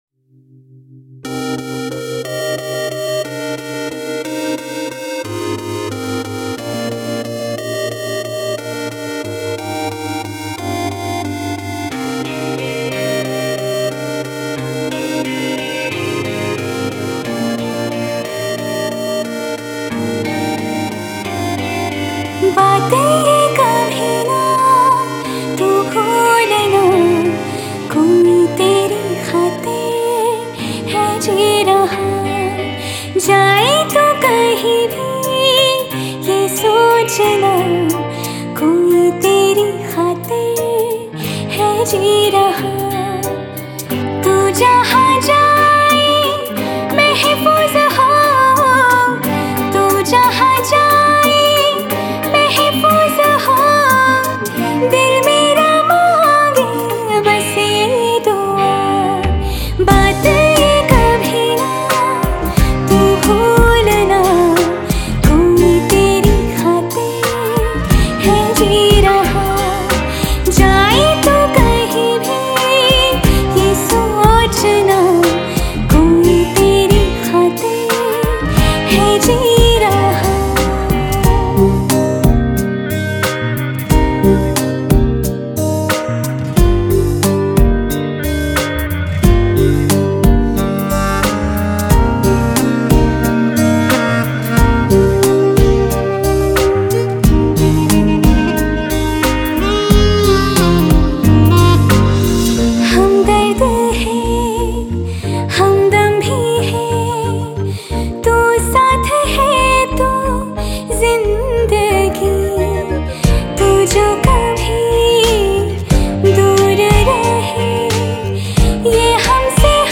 Bollywood Mp3 Music